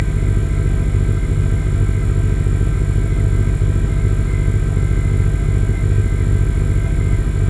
groanmachine.wav